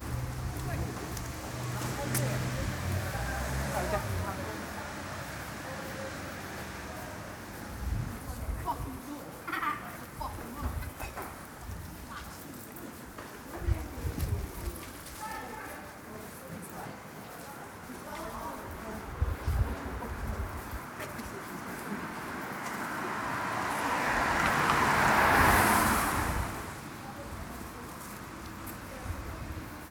Street life
Even during a quiet patch of the day, there’s no escaping boys and cars. Always cars.